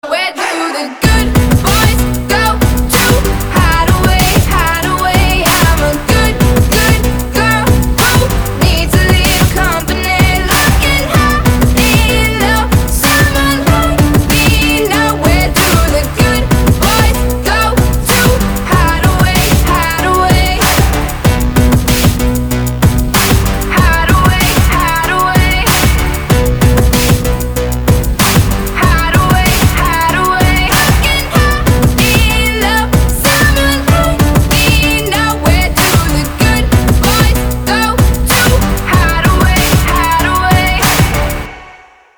• Качество: 320, Stereo
веселые